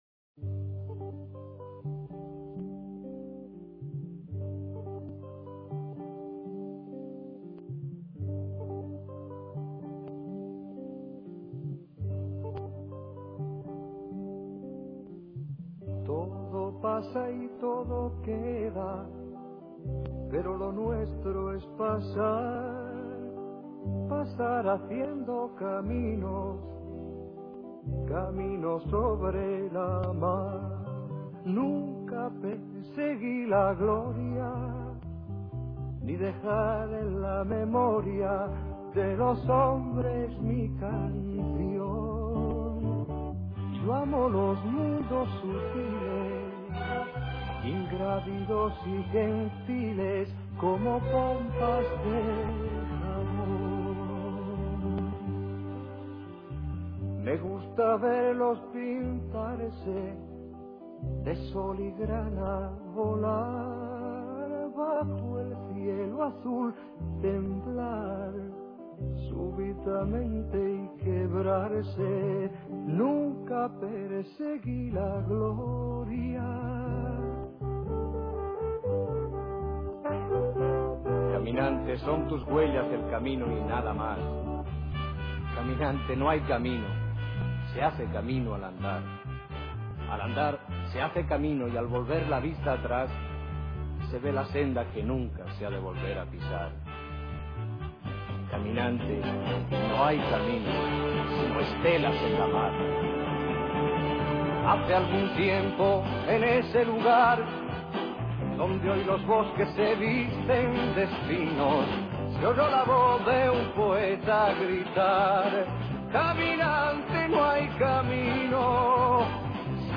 Calidad/Quality: Baja/Low - MP3 - 32 Kbps